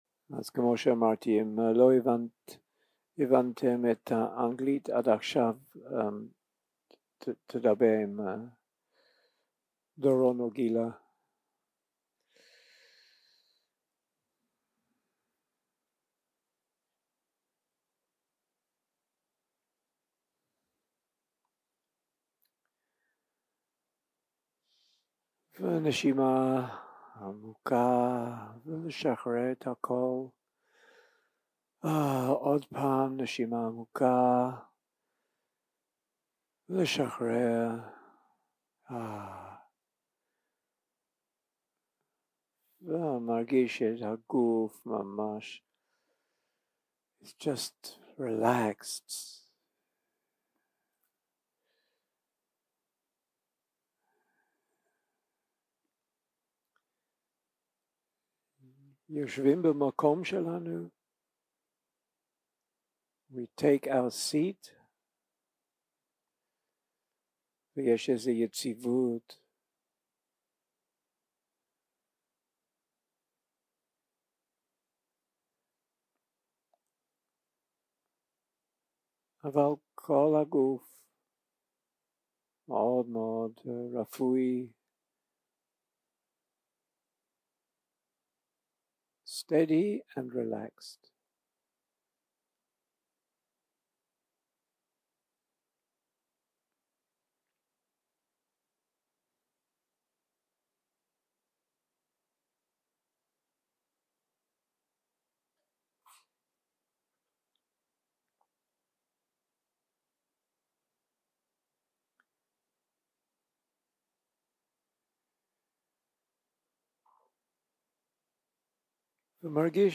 יום 1 - הקלטה 1 - ערב - מדיטציה מונחית